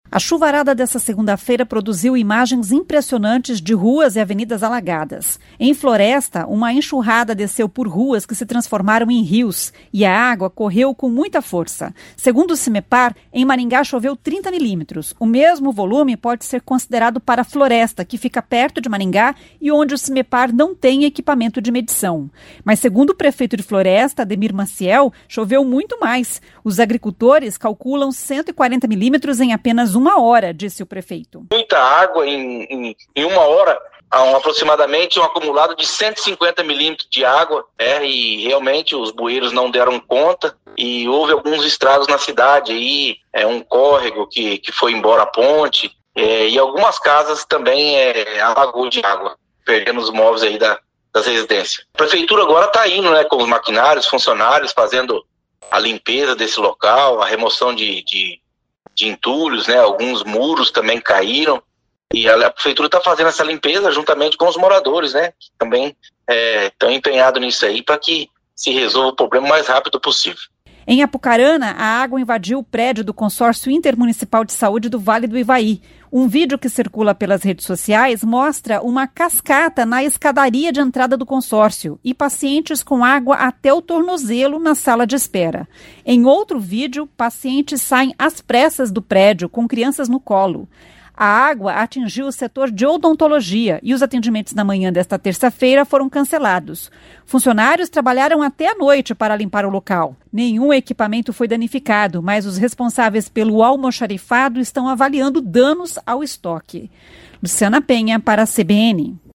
Mas segundo o prefeito de Floresta, Ademir Maciel, choveu muito mais. Os agricultores calculam 150 mm em apenas uma hora, disse o prefeito. [ouça o áudio]